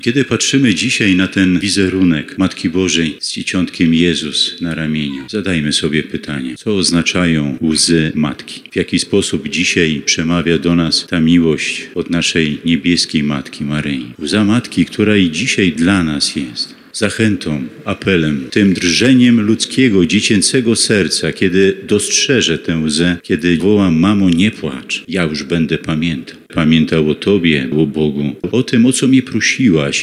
Wierni w Lublinie uczcili 74. rocznicę „Cudu Lubelskiego”. Na placu przed archikatedrą lubelską, w której 3 lipca 1949 roku na obrazie Matki Bożej pojawiły się łzy, odprawiona została uroczysta msza święta.
– Gromadzimy się tutaj, aby odnowić naszą wiarę – mówił podczas homilii biskup radomski Marek Solarczyk.